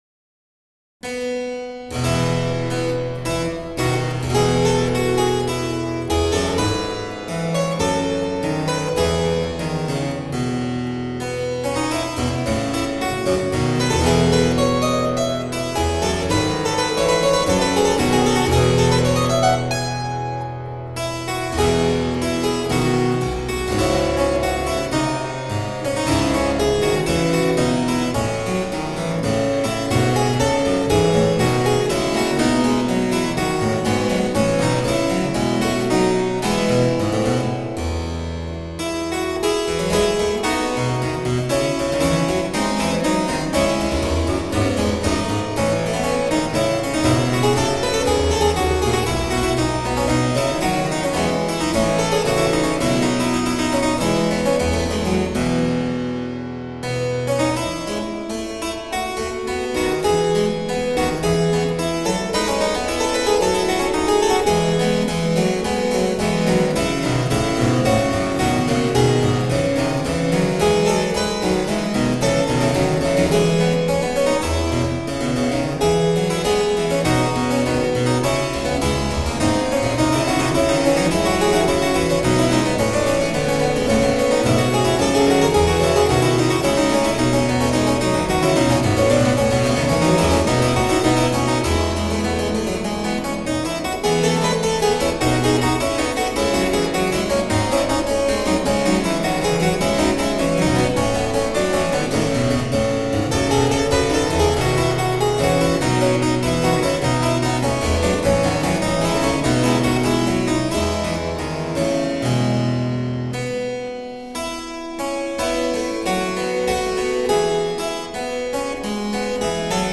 Deeply elegant harpsichord.